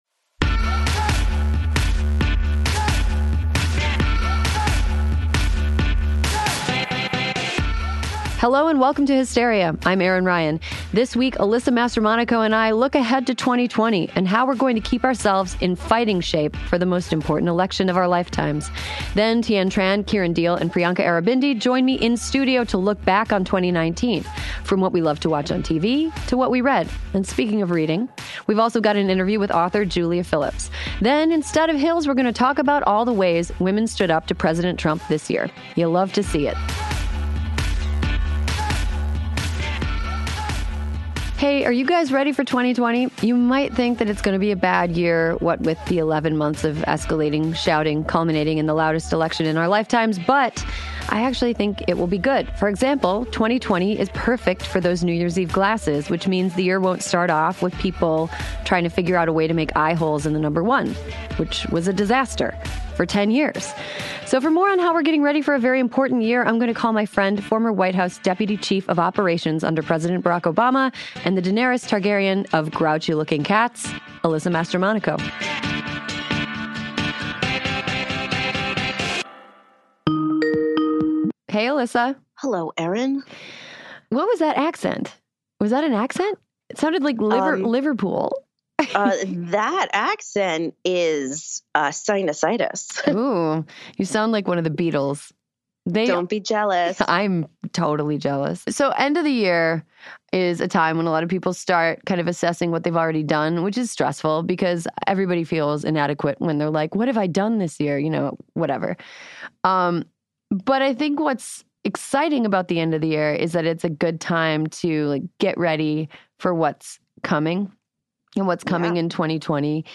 And speaking of reading: we’ve also got an interview with author Julia Phillips. Then, instead of hills, we’re going to talk about all the ways women stood up to President Trump this year.